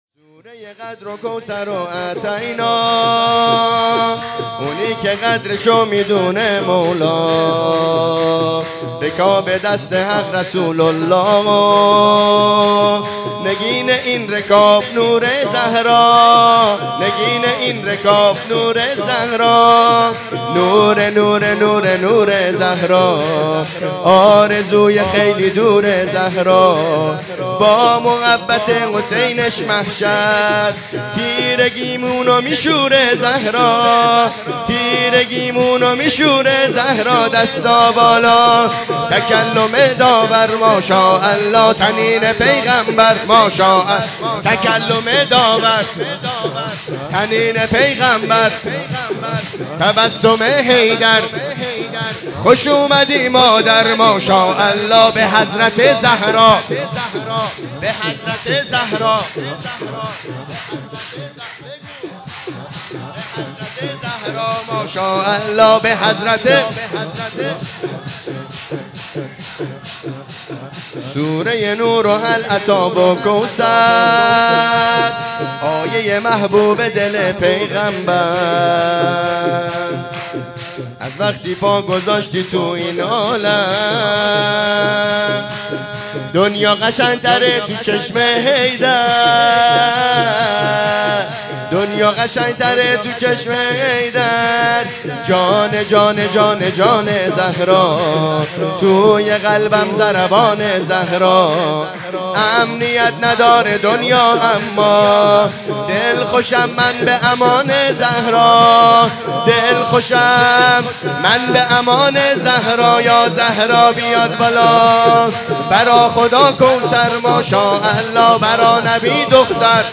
جشن ولادت حضرت زهرا سلام الله